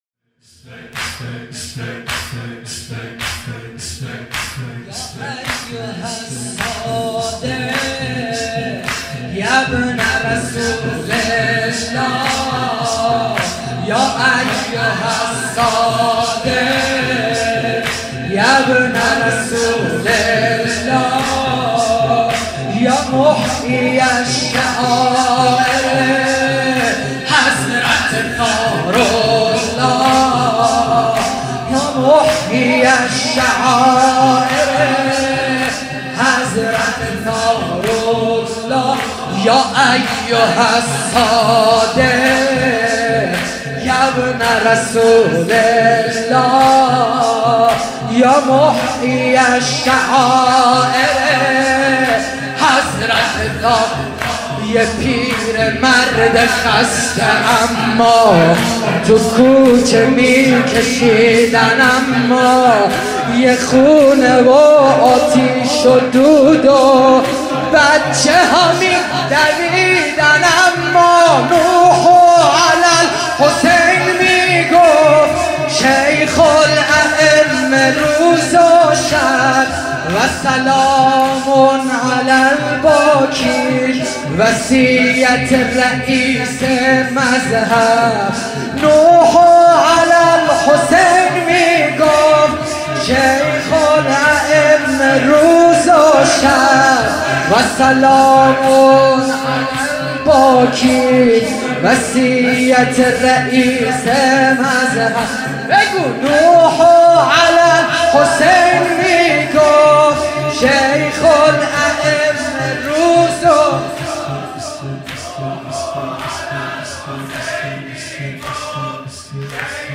زمینه | يا ايها الصادق، يا ابن رسول الله
مداحی
مراسم عزاداری شهادت امام جعفر صادق(ع)